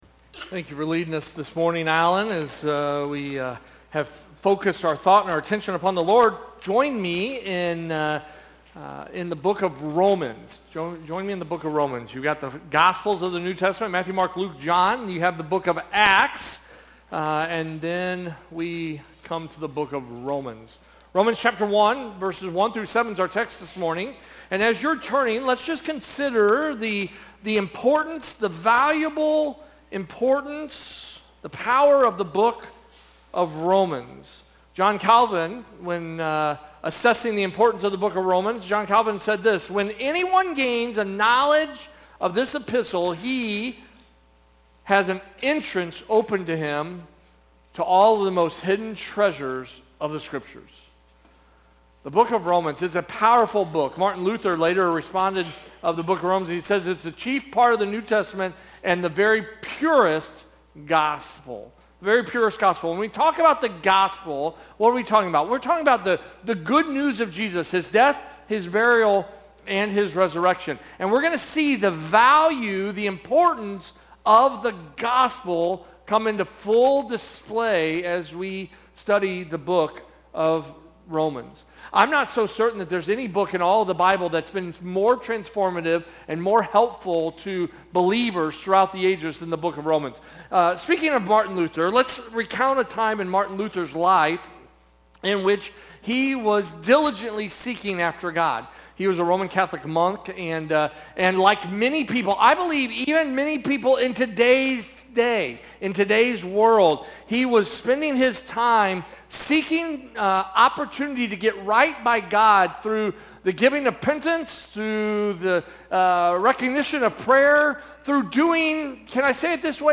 Sermons - First Baptist Church O'Fallon First Baptist Church O'Fallon